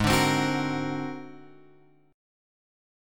G# 9th Flat 5th